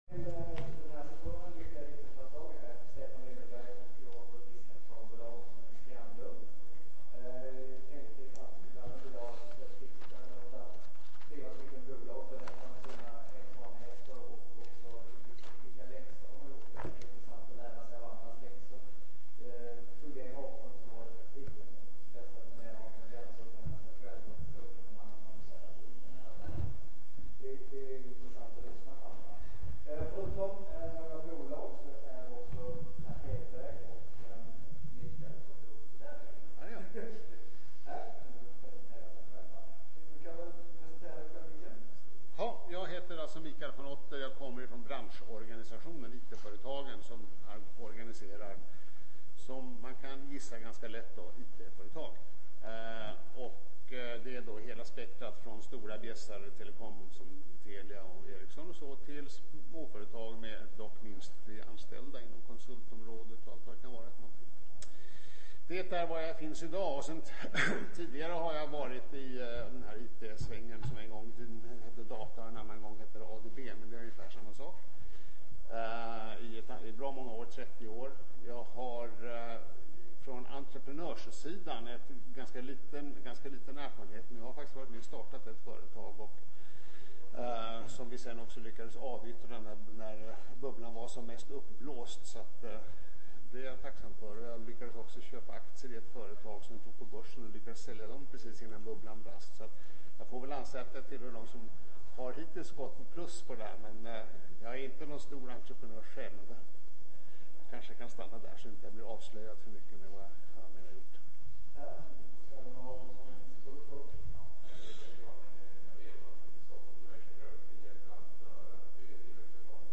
M�nniskor, teknik och kapital �r de viktigaste framg�ngsfaktorerna f�r nystartade teknikf�retag, men det g�ller att f� till den r�tta blandningen. N�gra f�retag som har lyckats ber�ttar hur de gick till v�ga. D�refter har vi en paneldebatt under ledning av en erfaren investerare.